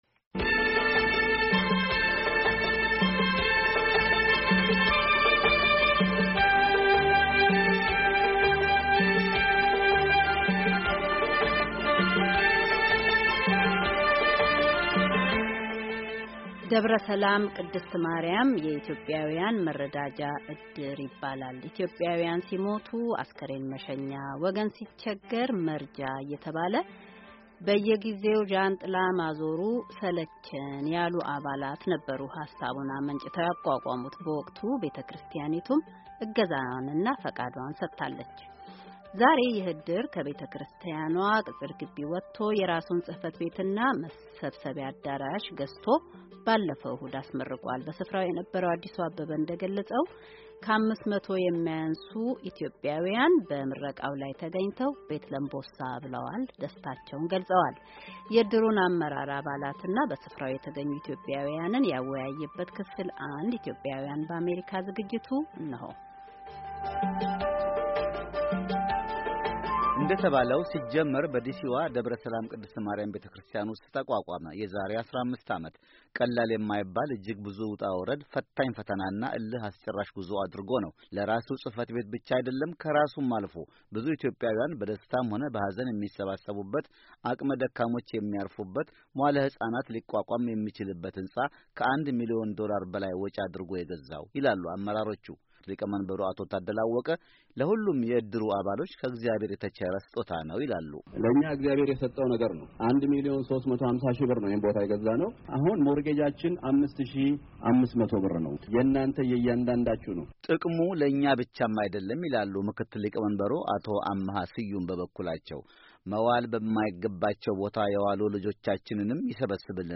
ደብረ-ሰላም ቅድስት ማርያም የኢትዮጵያውያን መረዳጃ ዕድር ይባላል። ኢትዮጵያውያን ሲሞቱ አስከሬን መሸኛ፣ ወገን ሲቸገር መርጃ እየተባለ በየጊዜው "ዣንጥላ ማዞሩ ሰለቸን" ያሉ አባላት ነበሩ ሃሳቡን አመንጭተው ያቋቋሙት። በወቅቱ ቤተ-ክርስቲያኒቱም እገዛና ፍቃዷን ሰጥታለች። የዕድሩን አመራር አባሎችና በስፍራው የተገኙ ኢትዮጵያውያንን ያወያየ ዝግጅት።